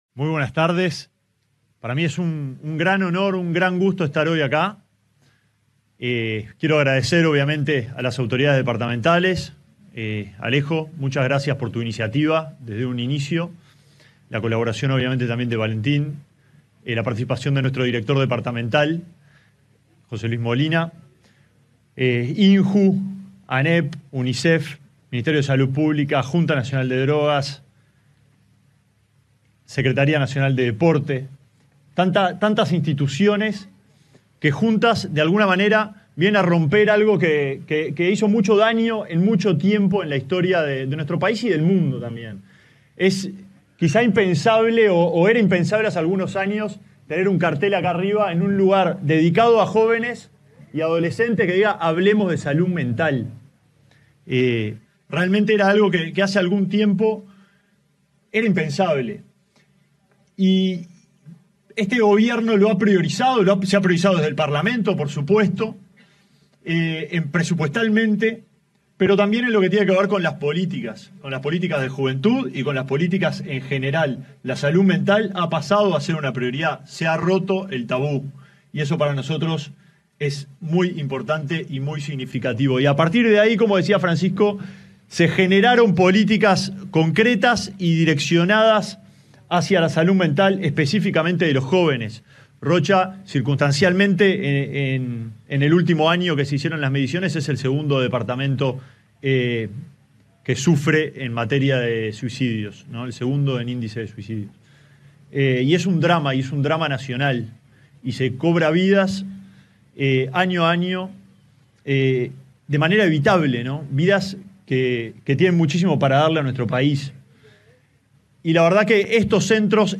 Palabras del ministro de Desarrollo Social, Alejandro Sciarra
Durante la inauguración del primero de siete centros ubicados en distintos puntos del territorio nacional, en el marco del programa nacional Ni